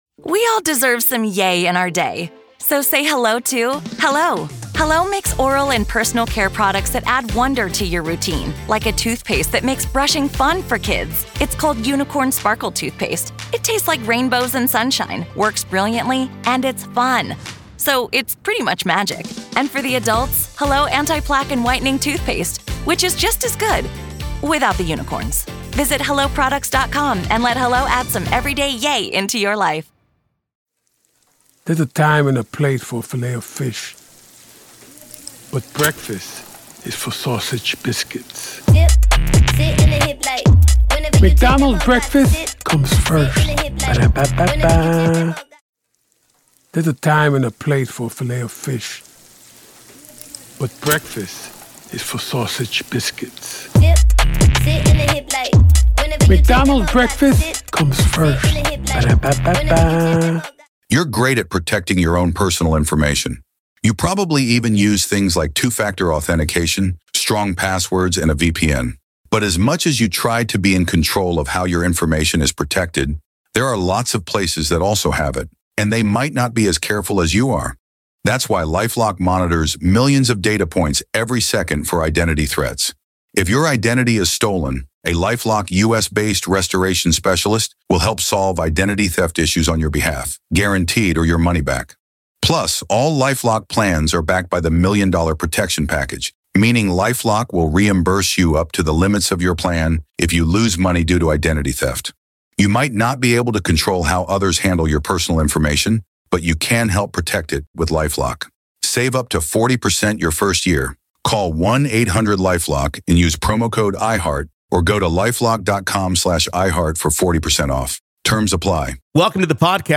The discussion peels back layers of speculation while exploring the unsettling reality of modern threats.